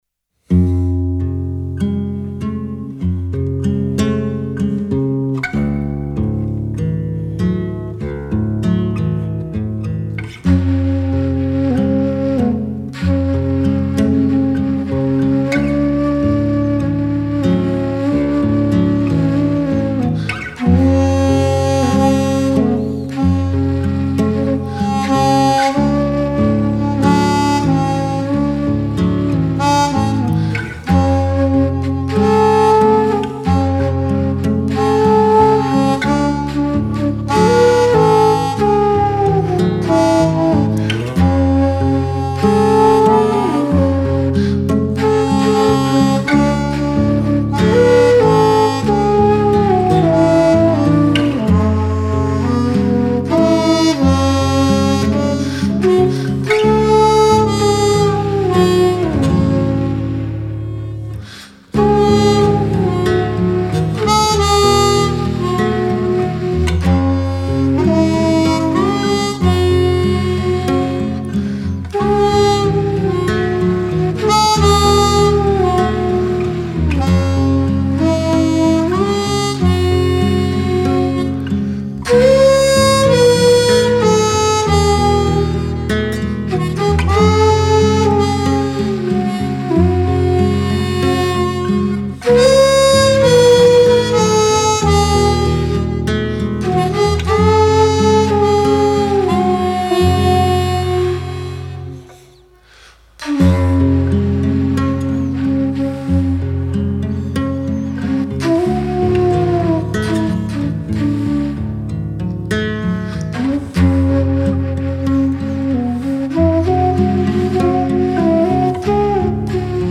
Genre: Acoustic World Music.